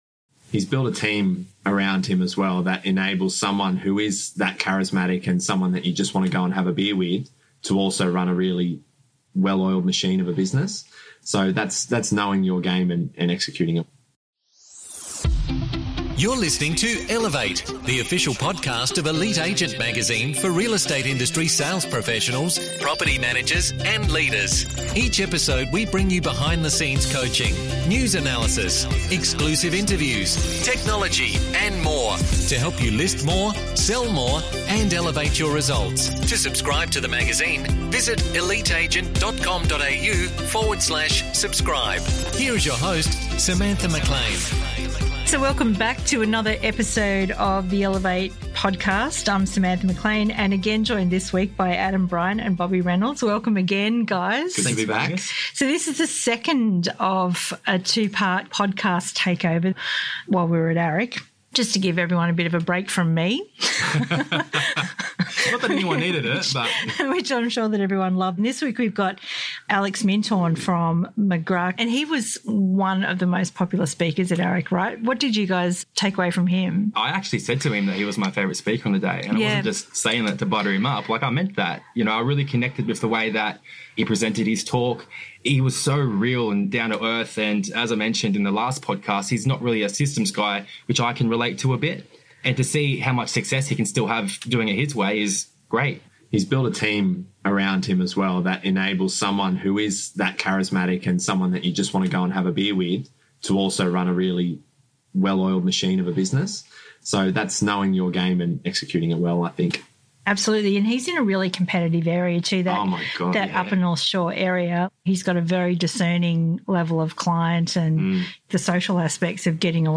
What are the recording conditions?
Having just stepped on to the AREC stage he gave the audience a 10-point plan and told them to be diligent and proactive.